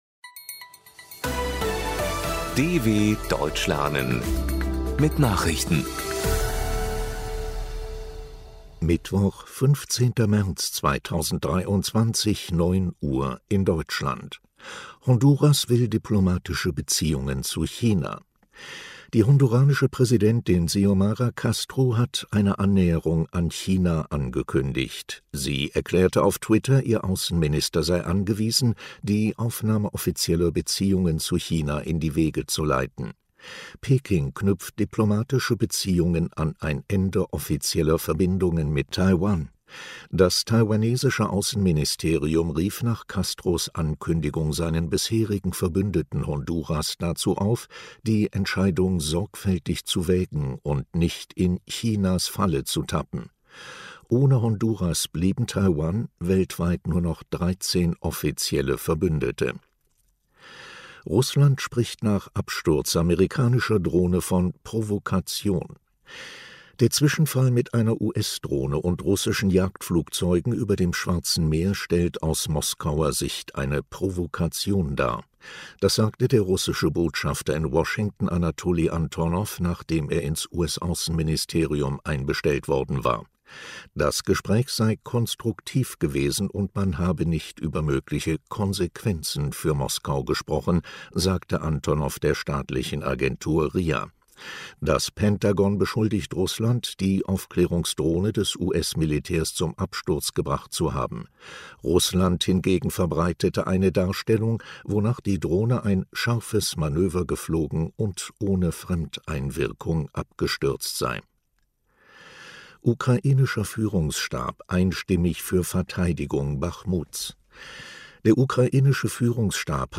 Langsam gesprochene Nachrichten
Trainiere dein Hörverstehen mit den Nachrichten der Deutschen Welle von Mittwoch – als Text und als verständlich gesprochene Audio-Datei.
Nachrichten von Mittwoch, 15.03.2023 – langsam gesprochen | MP3 | 7MB